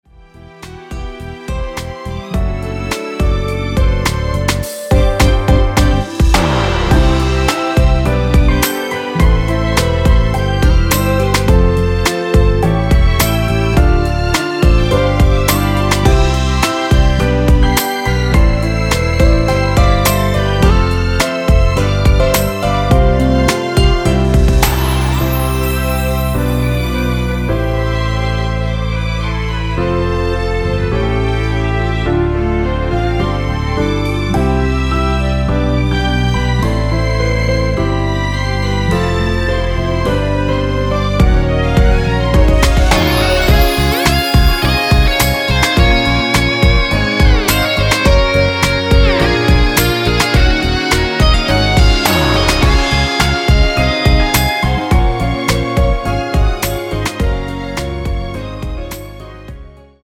원키에서(-8)내린 (1절+후렴)으로 진행되는 멜로디 포함된 MR입니다.
앞부분30초, 뒷부분30초씩 편집해서 올려 드리고 있습니다.
중간에 음이 끈어지고 다시 나오는 이유는